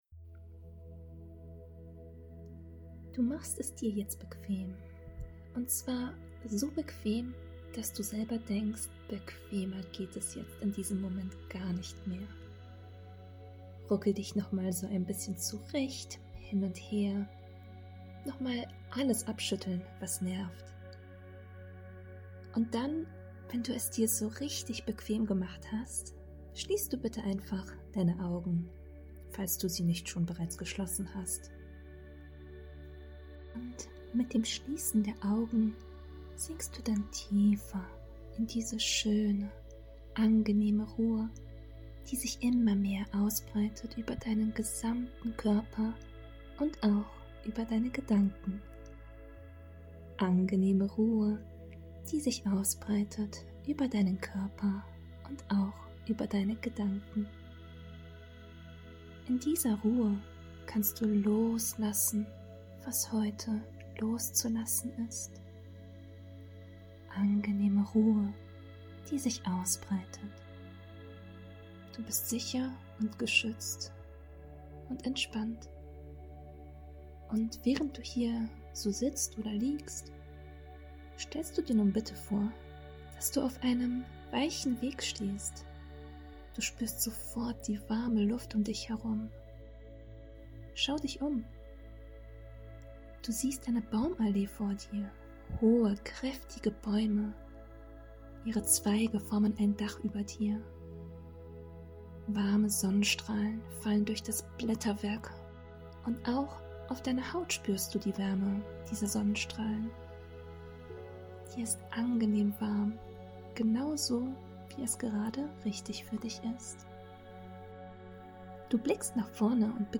entspannende 10-minütige Imaginationsreise
ImaginationsreiseBS.MP3